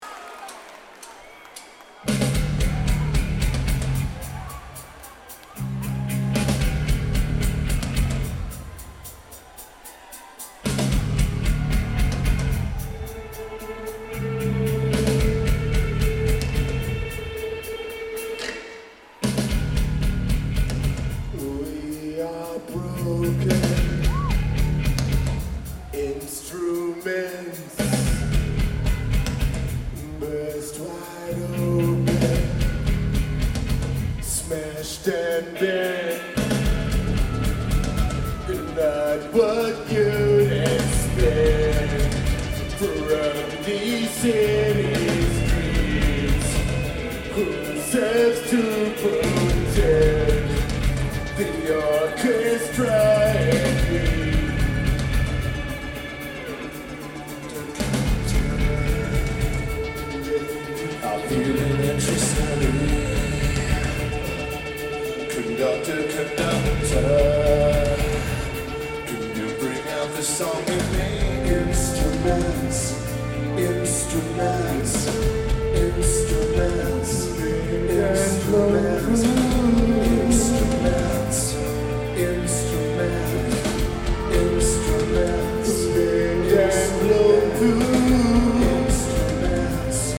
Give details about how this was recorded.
Lakewood Amphitheatre Lineage: Audio - AUD (Zoom H4) Again, these Zoom's internal mics are very good.